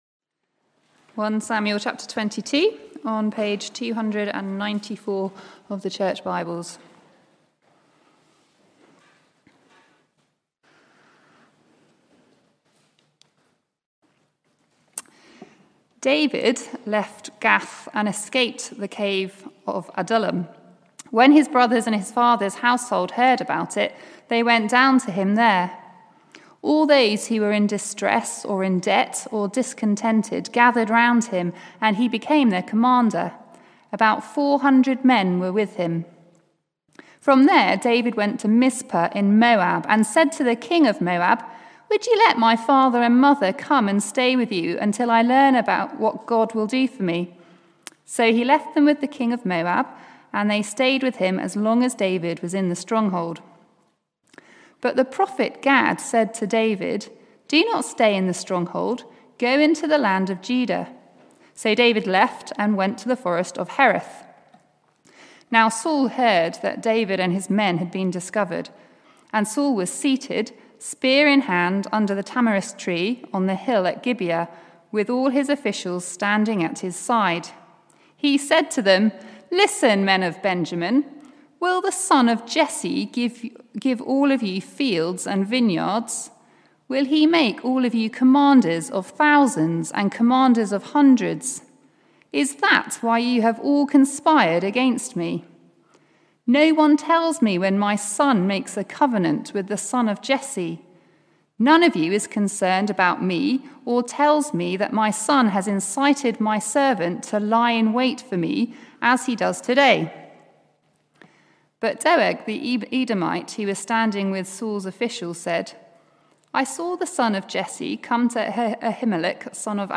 Sermons Archive - Page 40 of 188 - All Saints Preston